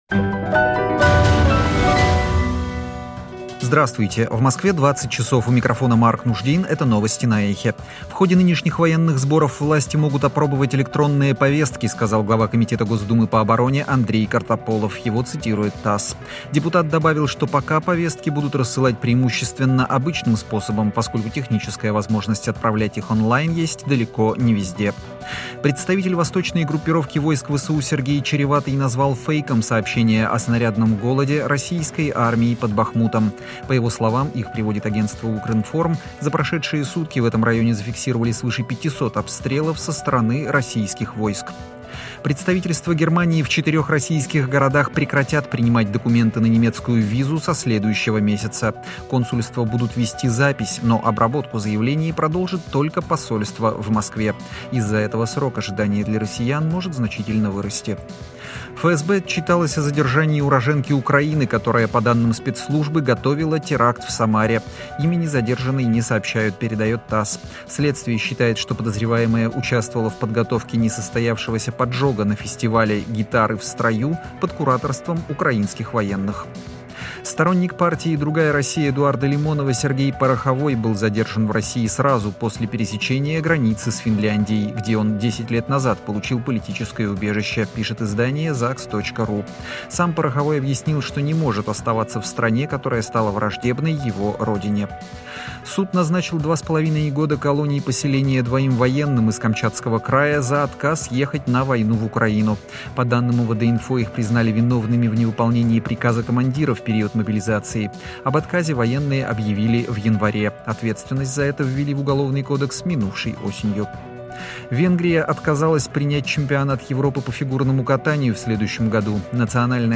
Слушайте свежий выпуск новостей «Эха».